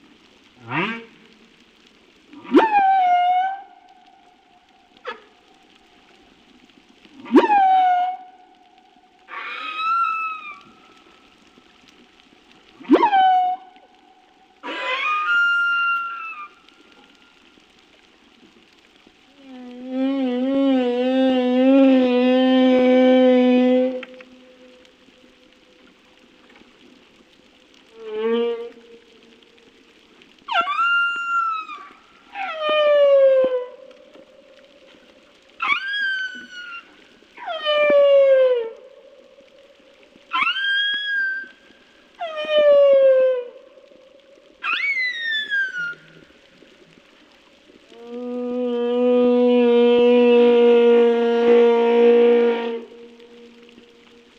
humpbacksounds2.wav